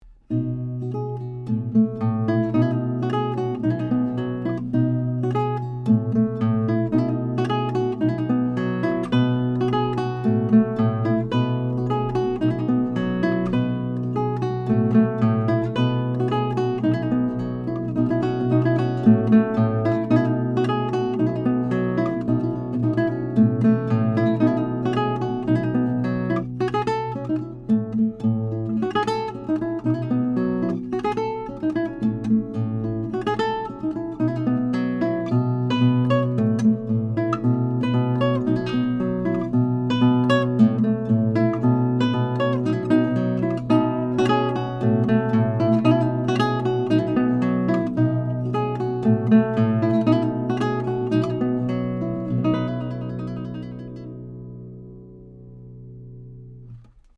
MILAGRO 11-String Classical Harp Guitar
I have the guitar tuned in Romantic tuning in to G, a standard 11-string tuning where 1-6 are tuned up a minor third, and 7-11 descend step-wise: 7=D, 8=C, 9=B, 10 =A, 11=G. It can also be tuned in Dm tuning for Baroque Dm lute music.
Here are 12 quick, 1-take MP3s of this guitar, tracked using a Neumann TLM67 mic, into a Undertone Audio MPEQ-1 preamp using a Metric Halo ULN8 converter going into Logic. This is straight, pure signal with no additional reverb, EQ or any other effects.
3 | Corn Yards (Scottish Renaissance,